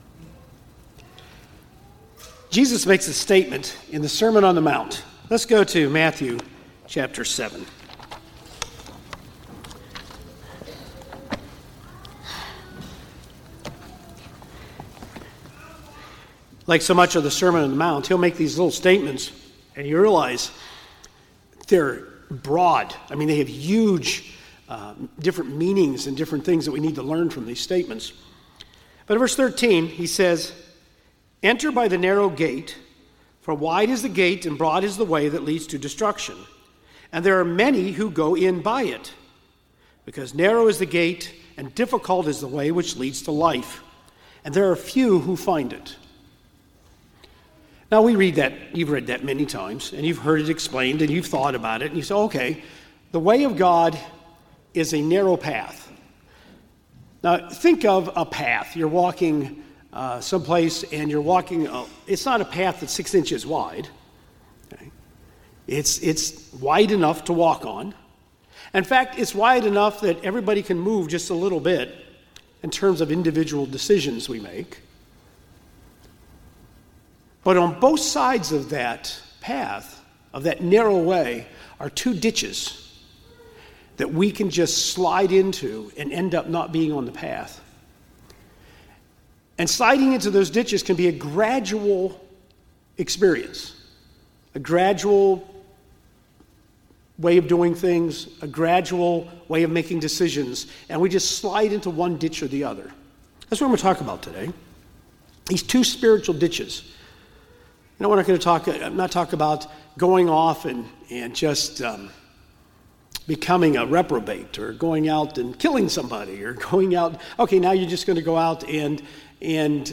Spiritual Ditches | United Church of God